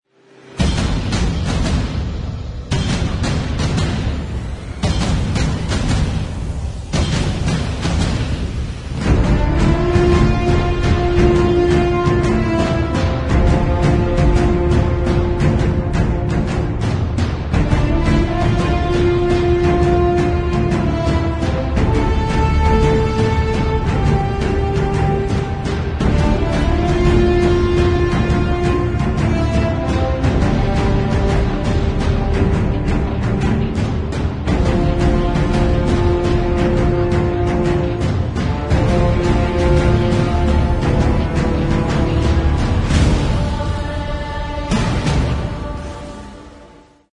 Cine y Televisión